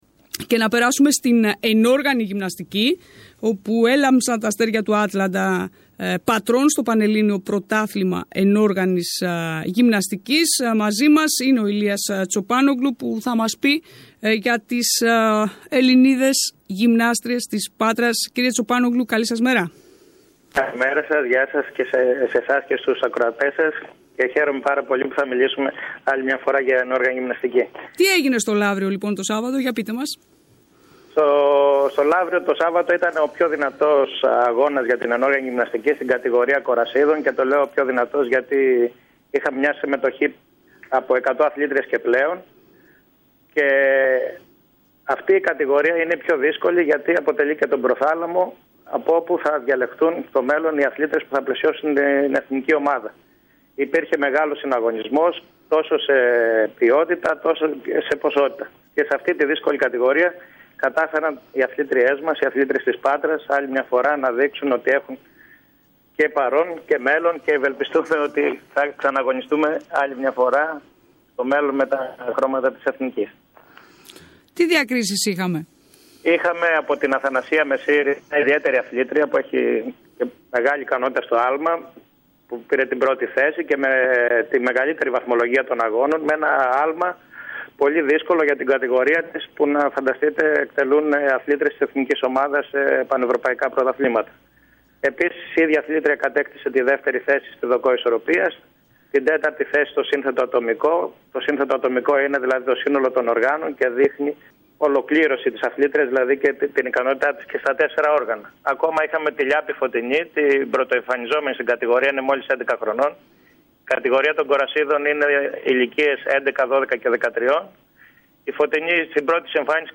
μίλησε στην ΕΡΤ  Πάτρας και στην εκπομπή «Αθλητικό Μαγκαζίνο»